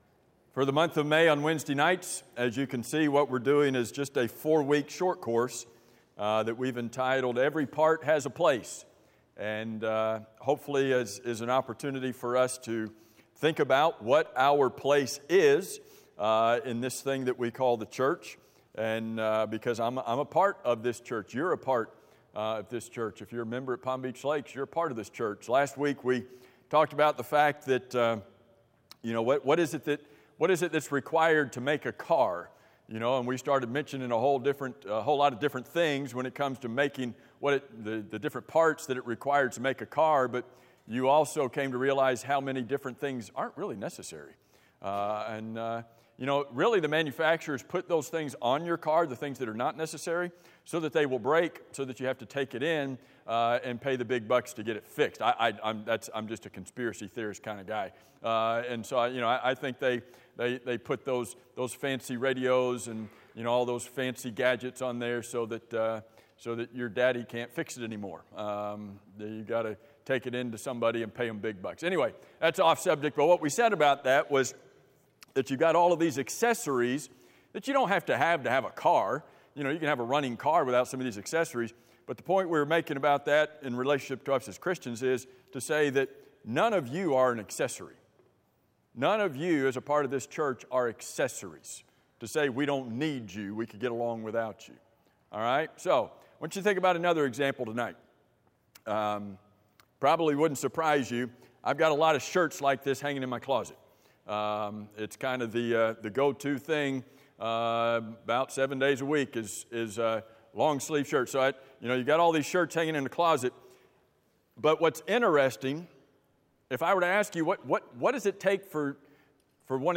in the Church Preacher